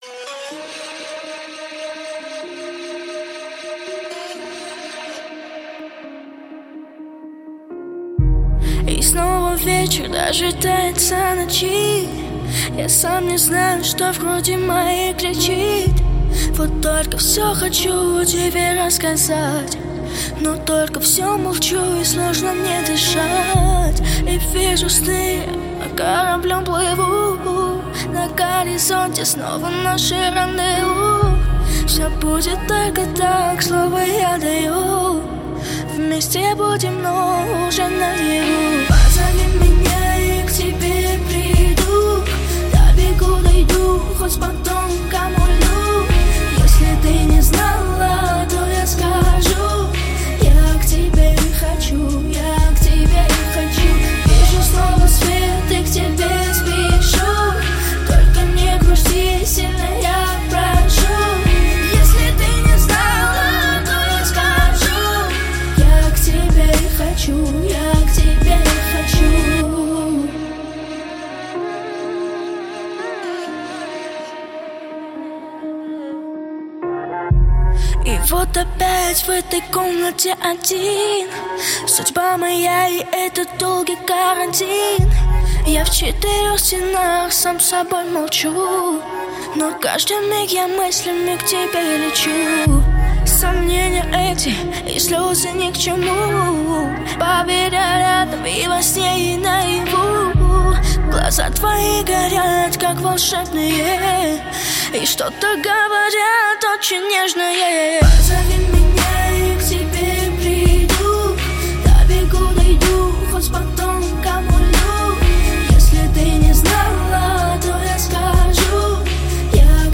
• Категория: Детские песни
восточные мотивы